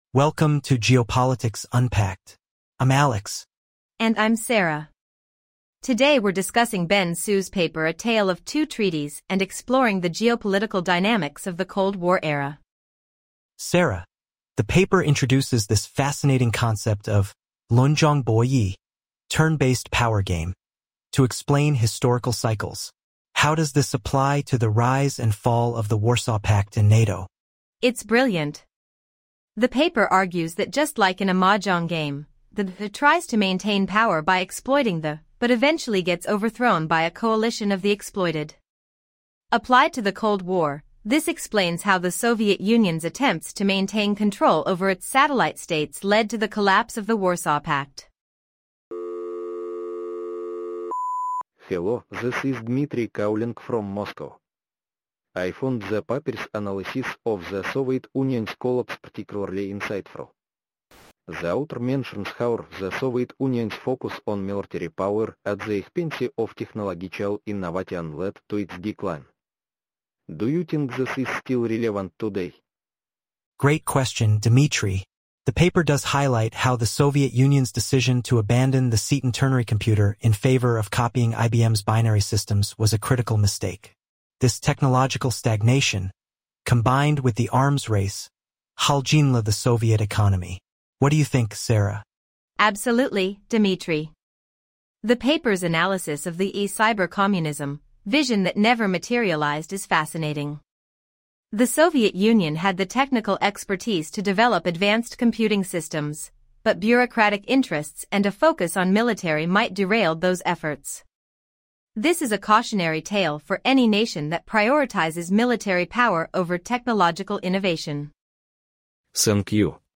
Initial commit for TTS project
multi_guest_callin_podcast.mp3